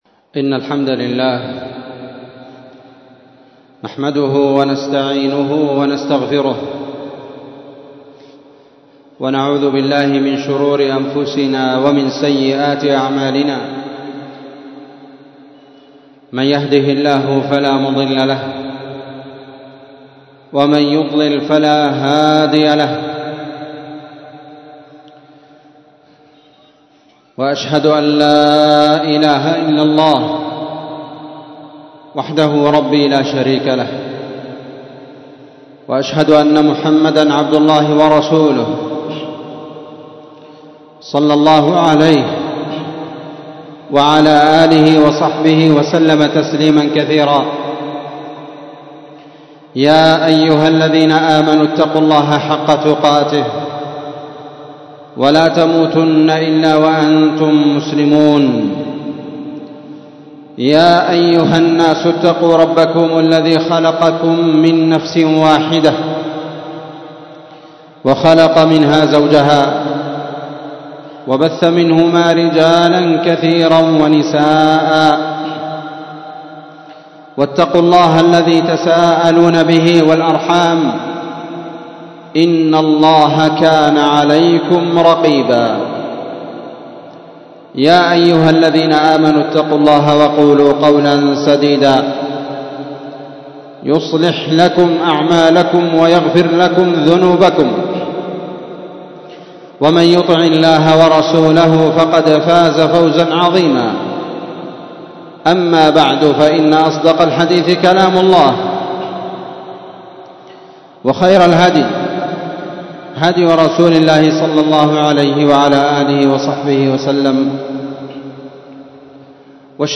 *خطبة الجمعة* ????????
مسجد المجاهد- النسيرية- تعز ????٢٠ شعبان ١٤٤٥ه‍.